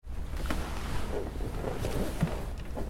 Звук посадки водителя в легковой автомобиль